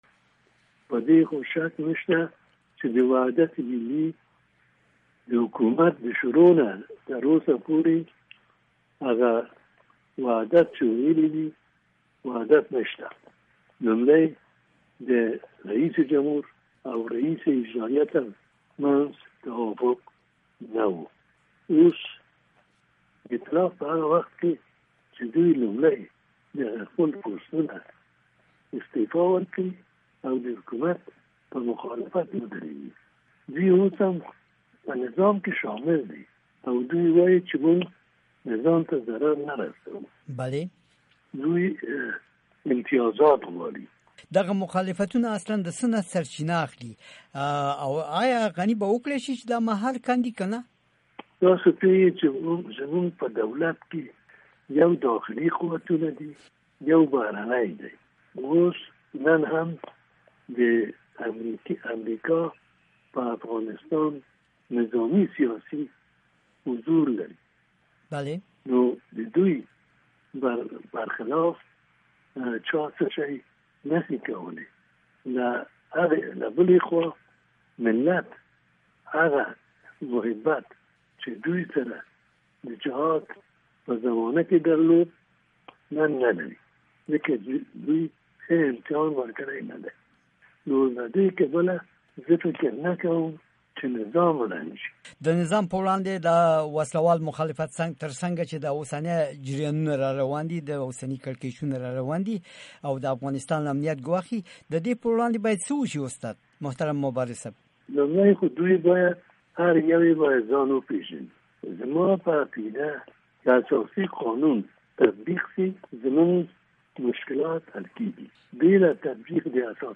د افغانستان د پیژندل شوي ژورنالیست عبدالحمید مبارز مرکه